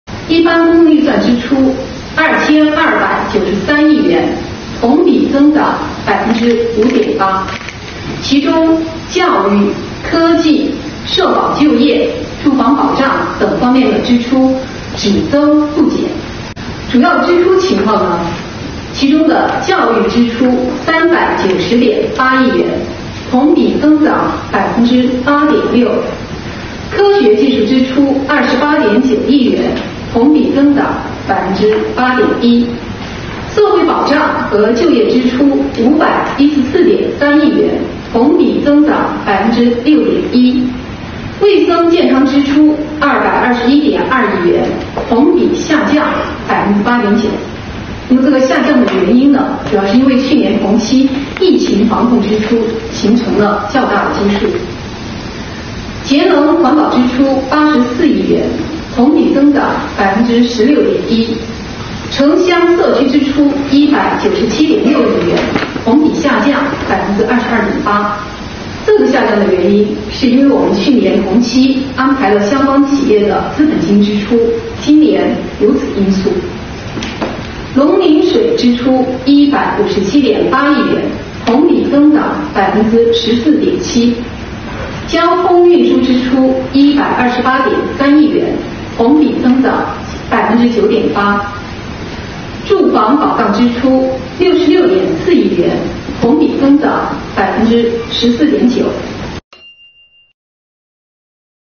重庆财政收支执行情况新闻发布会
李 虹 市财政局党组成员、副局长、新闻发言人